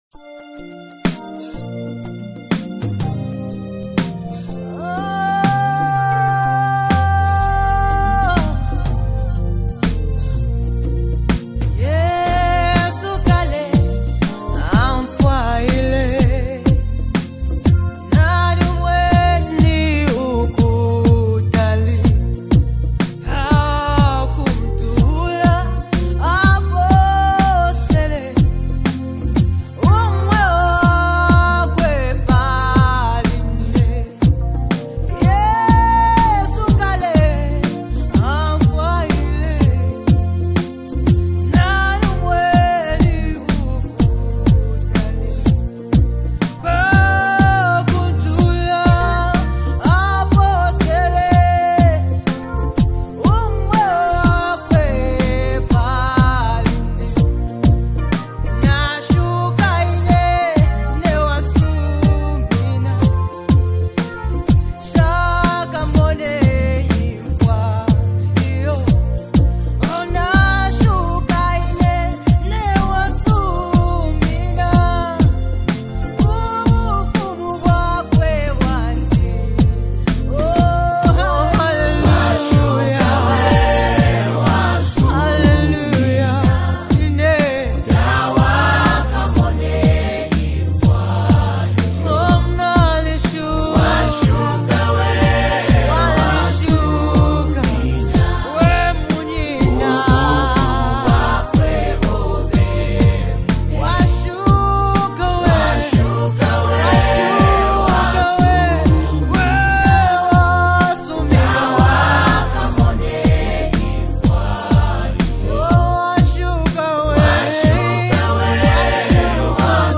Best Classic Worship Song
soothing melodies